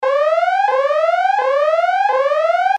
тревога